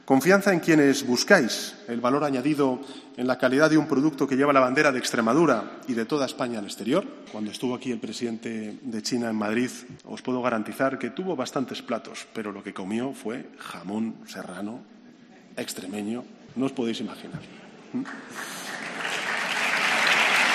El presidente del Gobierno en funciones confunde 'el jamón ibérico con el serrano' en la inauguración de la feria ganadera más importante de España
El tremendo error de Sánchez en la inauguración de la Feria Ganadera de Zafra.